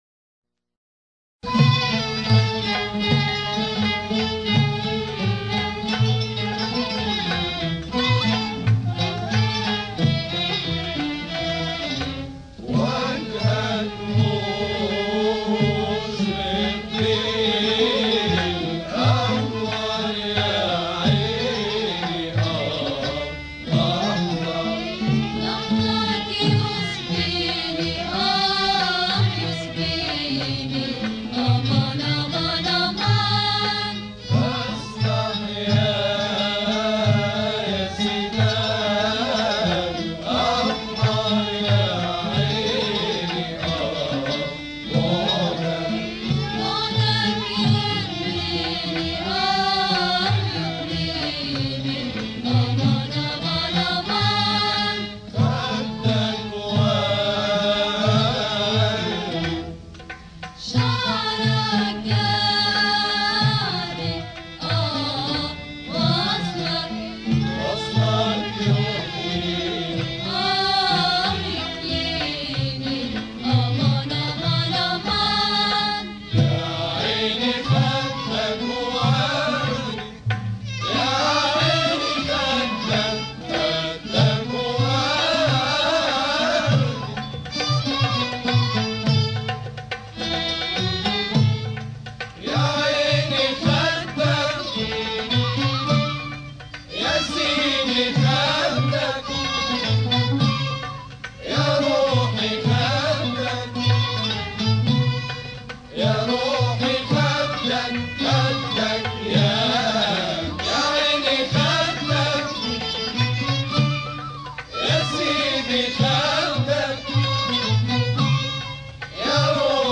Two Muwashahat